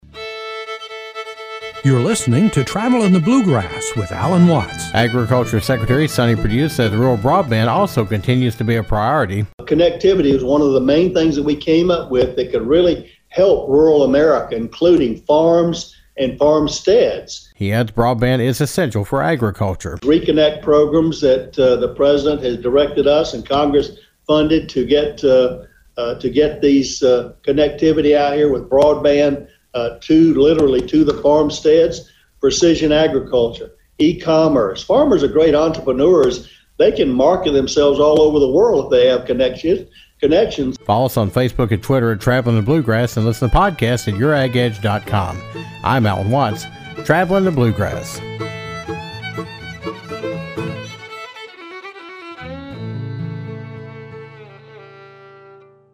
The United States has made progress with the USMCA and other trade agreements around the world.  Secretary of Agriculture Sonny Perdue discusses the USMCA and other trade agreements, and the Secretary provides an update on broadband expansion.